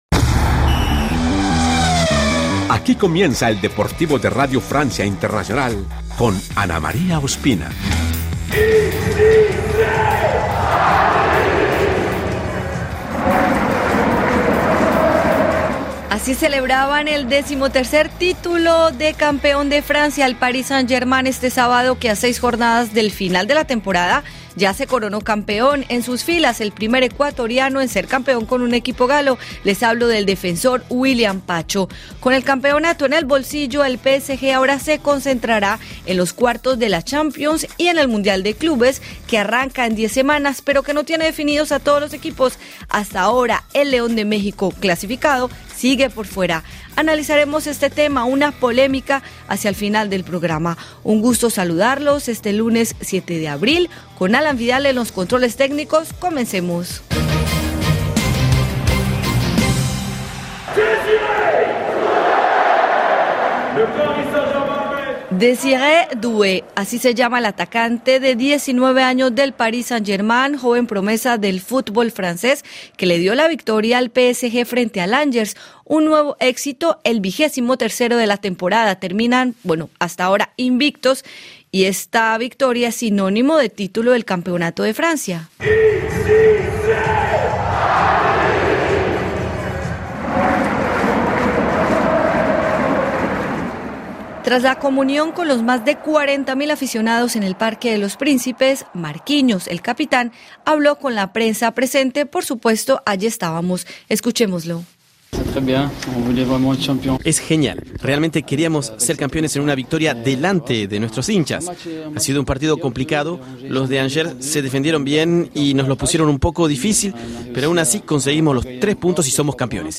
Fue una ocasión perfecta para entrevistar a una de las raquetas más sólidas y sorprendentes de la representación latinoamericana en el circuito ATP, el chileno Alejandro Tabilo, que hoy podrán escuchar en exclusiva los oyen